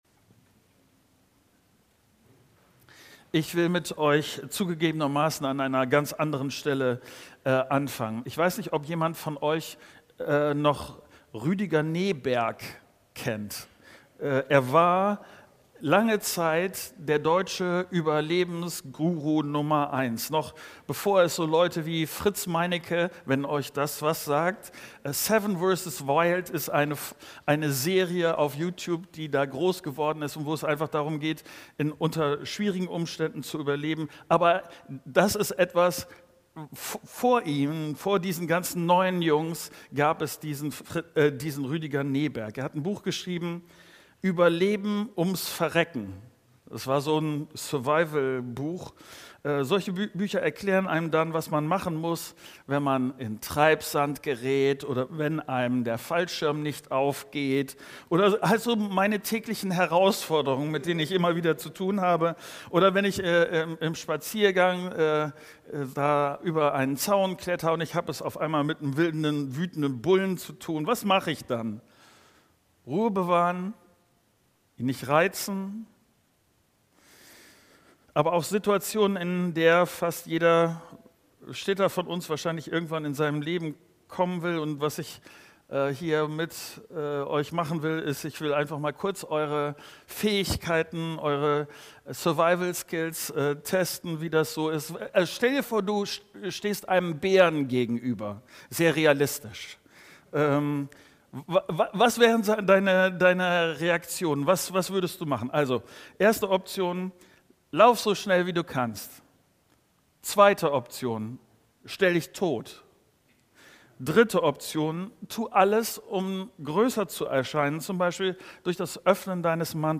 Dateien zum Herunterladen Update Predigt als MP4